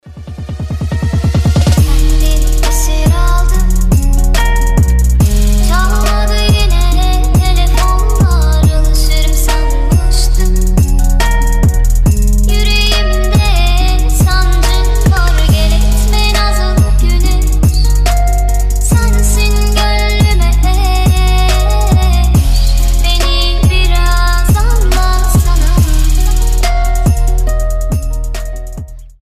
• Качество: 320, Stereo
мелодичные
красивый женский голос
арабские
ремиксы